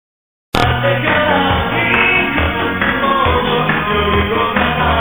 echo.mp3